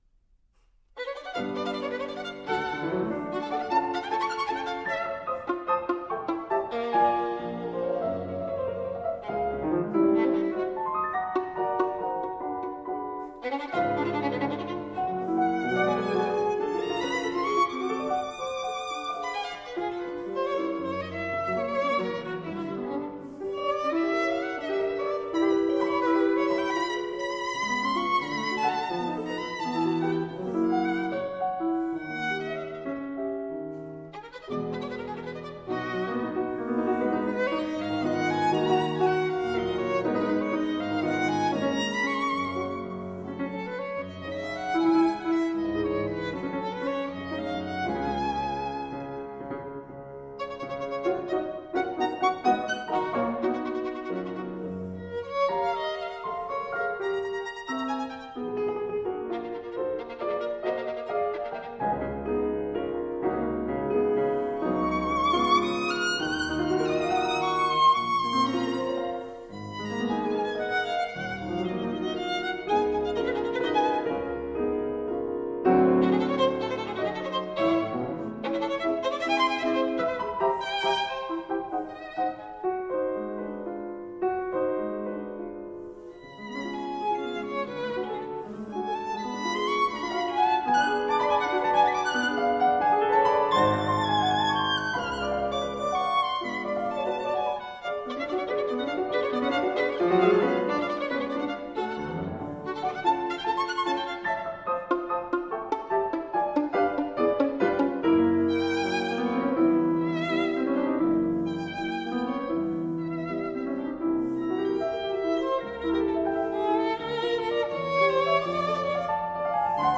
Sonata for Violin and Piano in d minor